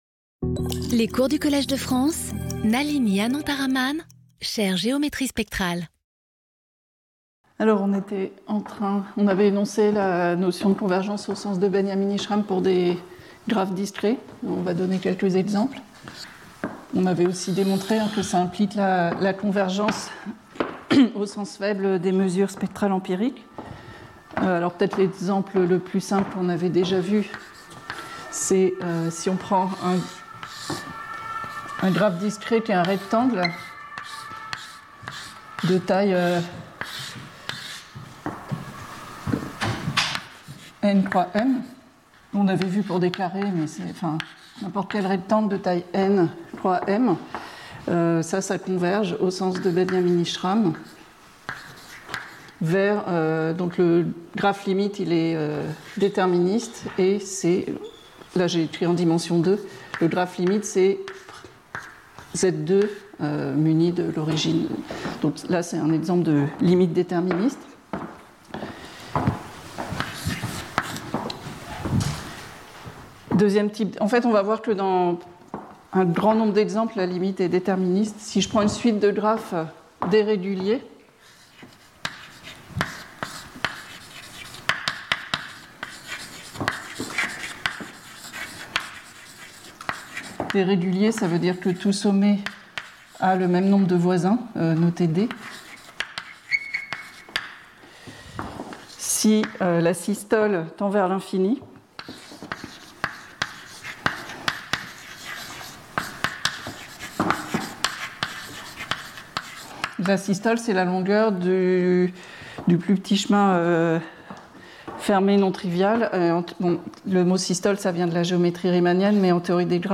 Nalini Anantharaman Professeure du Collège de France
Cours